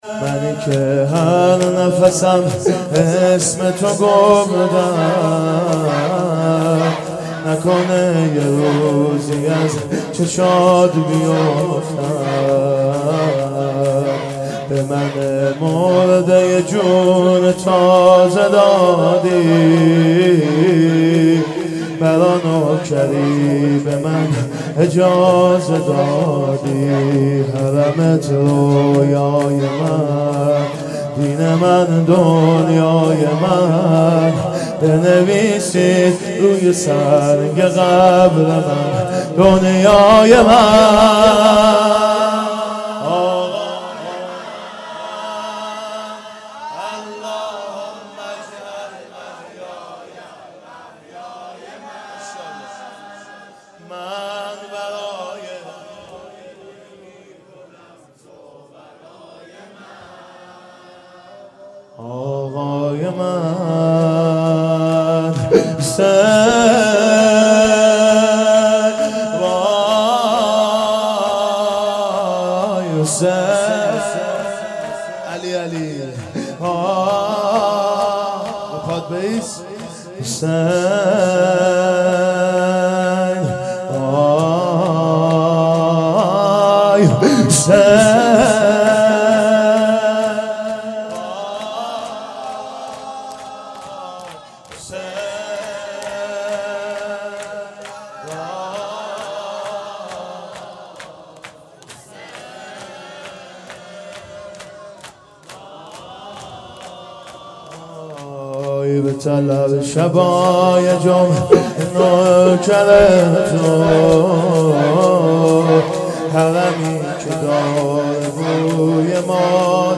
منی که هر نفسم اسم تو گفتم _ شور
محرم 1440 _ شب چهارم